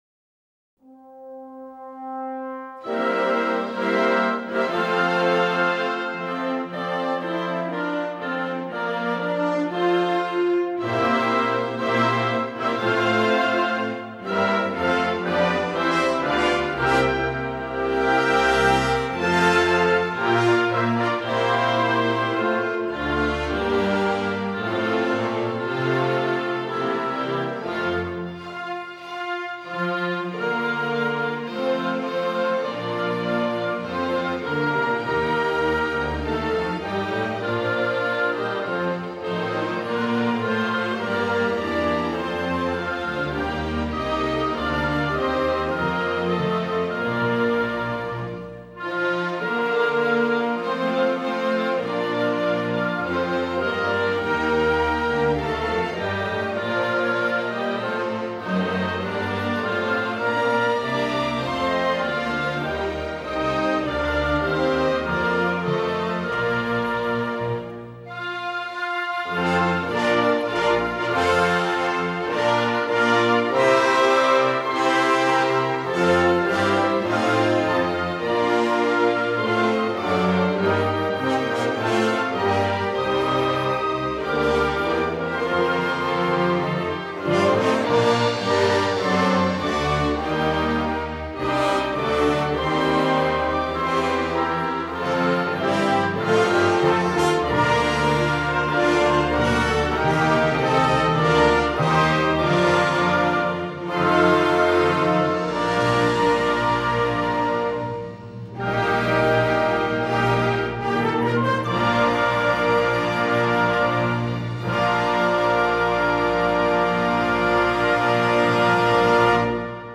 Original Music Soundtrack